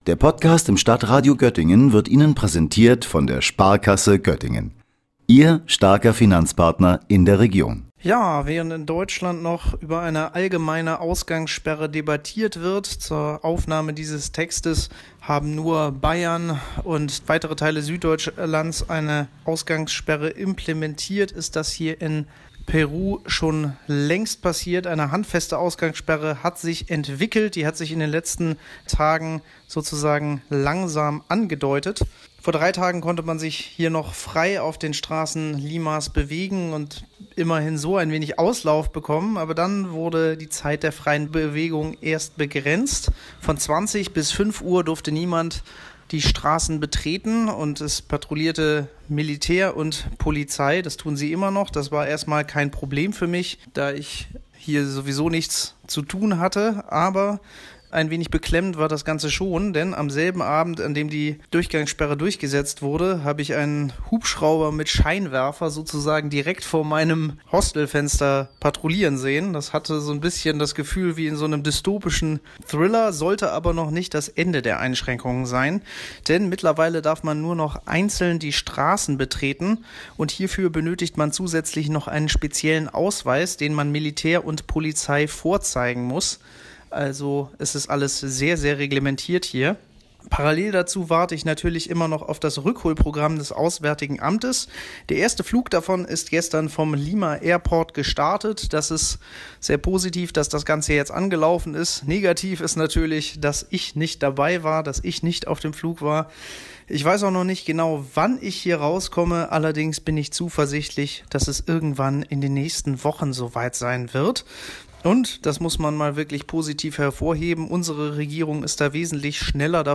per Sprachnachricht berichtet